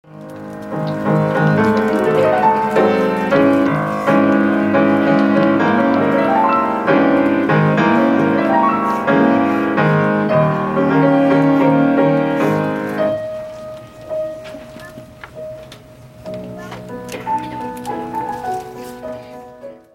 �|���l�[�Y��1�ԁi�V���p���j 19.3�b�̃��[�r�[�ł��B�掿�����̓C���^�[�l�b�g�̓s����A���e�͉������B QuickTime Movie (923KBytes) (�Đ��ɂ�QuickTime Plug-in���K�v�ł��j RealAudio/Video (�Đ��ɂ�Real/video Player���K�v�ł��j